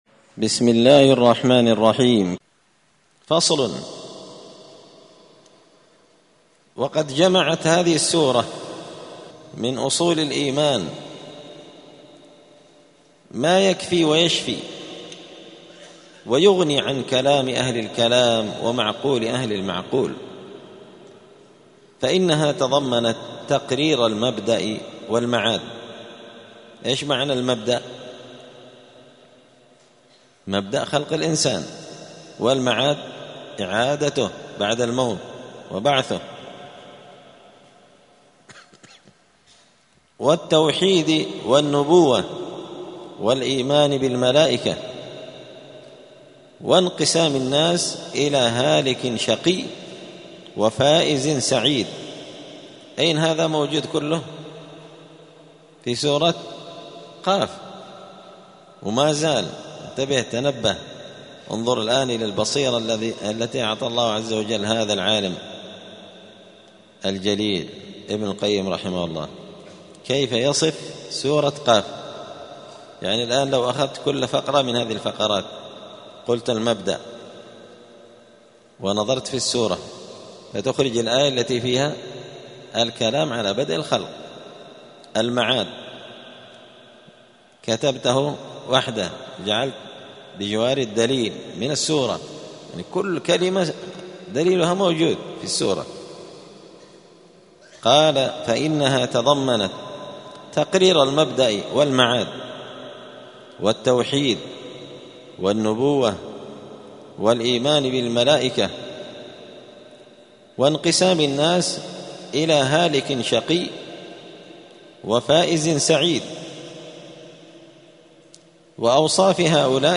*الدرس الثالث (3) (فصل: وقد جمعت هذه السورة من أصول الإيمان ما يكفي ويشفي)*